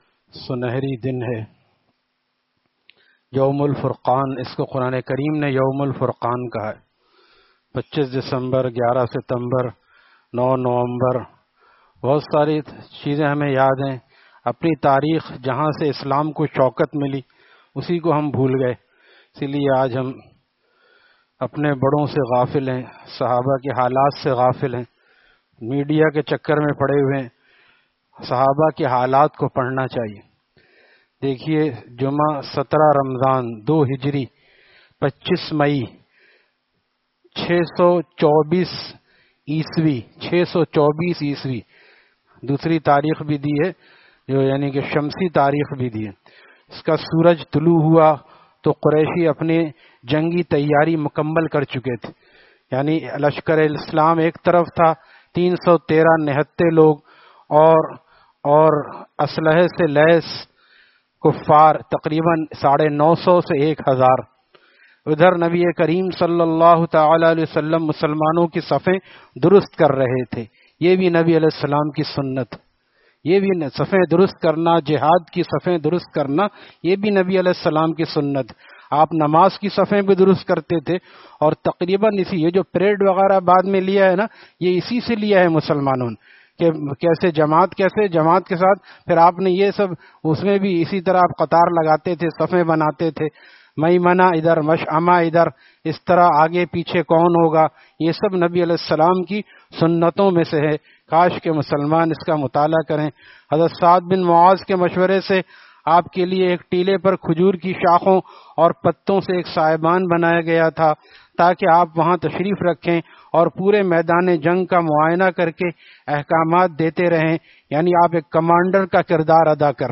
Taleem After Fajar at Jamia Masjid Gulzar e Muhammadi, Khanqah Gulzar e Akhter, Sec 4D, Surjani Town